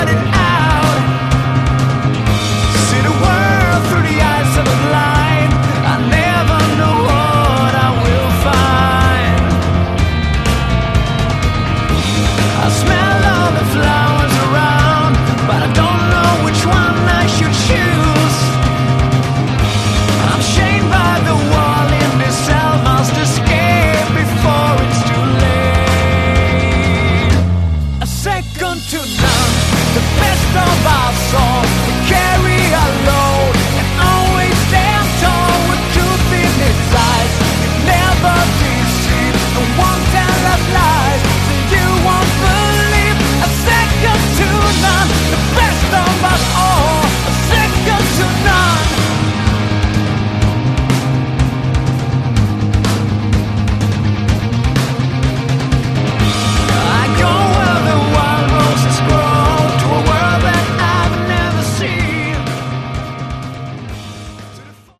Category: Hard Rock
vocals, guitars
guitars
bass
drums
Great mix of heavy guitars and melodic song writing.
Very melodic yet heavy enough to keep your interest.
Hooks of the highest order along with big choruses.